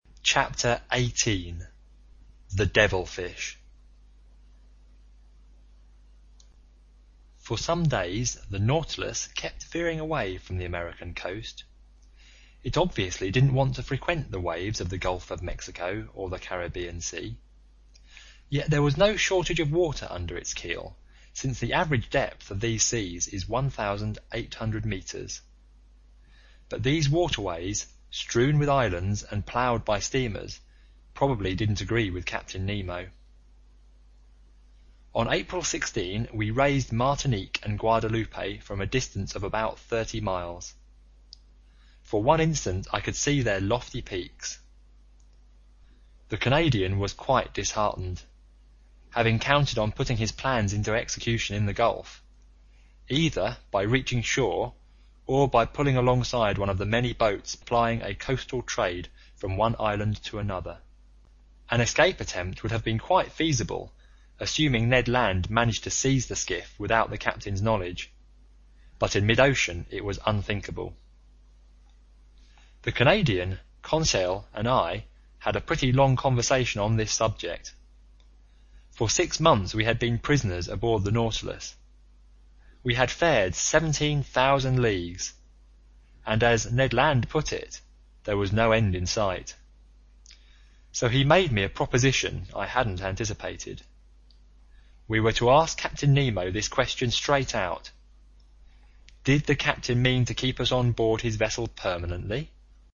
英语听书《海底两万里》第493期 第31章 章鱼(1) 听力文件下载—在线英语听力室
在线英语听力室英语听书《海底两万里》第493期 第31章 章鱼(1)的听力文件下载,《海底两万里》中英双语有声读物附MP3下载